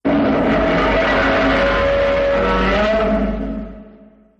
Another Godzilla roar used in the original 1954 film.
Another_Godzilla_1954_roar.ogg